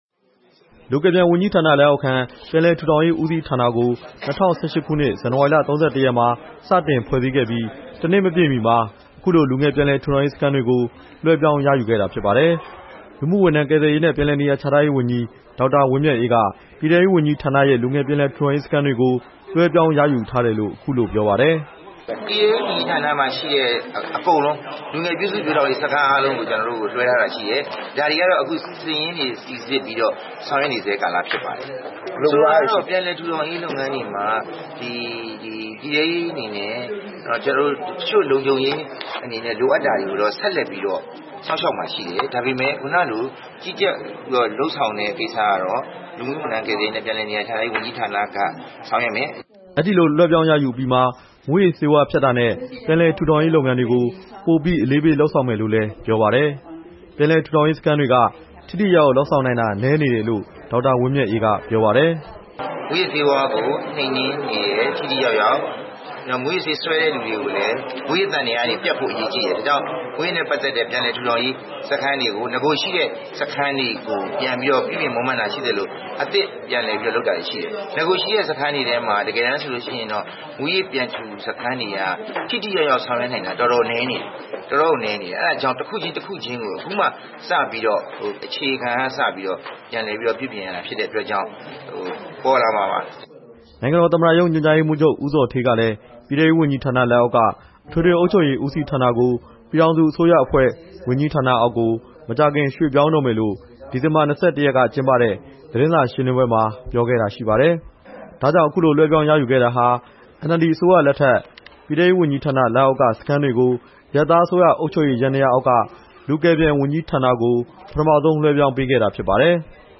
နေပြည်တော် မင်္ဂလာသီရီဟိုတယ်မှာ ဒီနေ့ ကျင်းပတဲ့ အလုပ်ရုံဆွေးနွေးပွဲတစ်ခုအပြီးမှာ ဒေါက်တာ ဝင်းမြတ်အေးက သတင်းထောက်တွေကို အဲဒီလိုပြောသွားတာပါ။